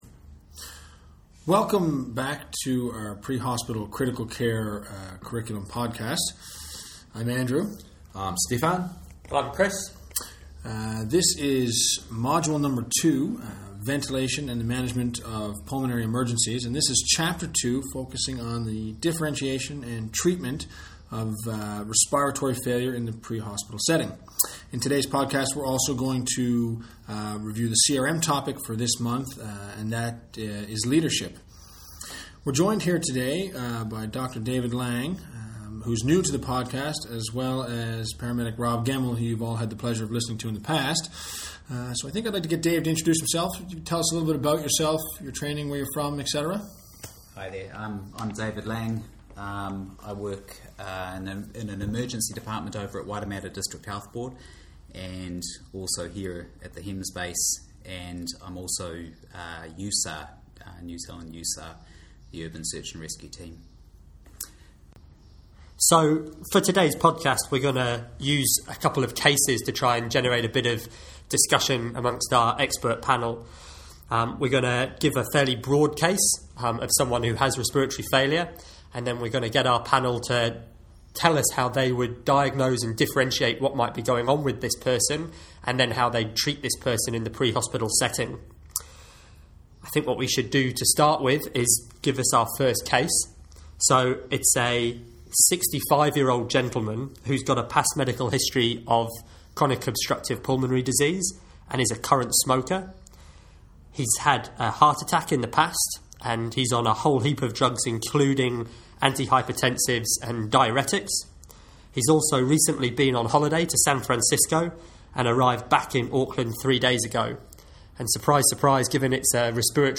The simulations to be completed in this module are closely related to the content contained in these lectures.